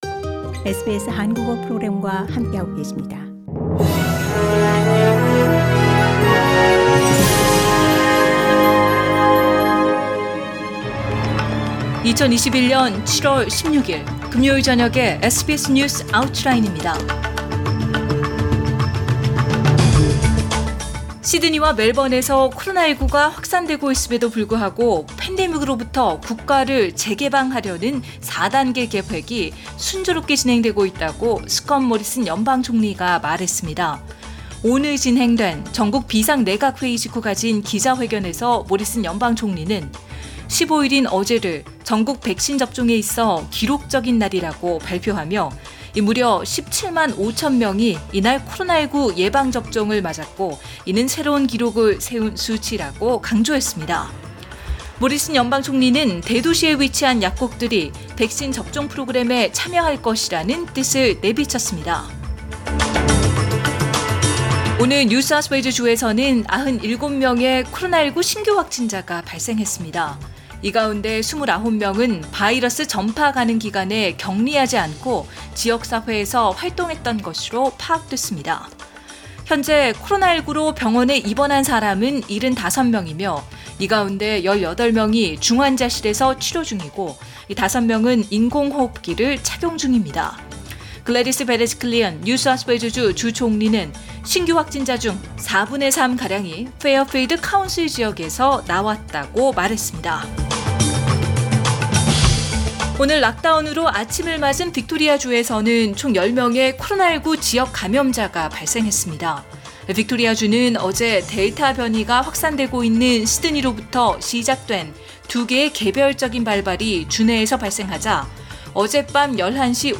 SBS News Outlines…2021년 7월 16일 저녁 주요 뉴스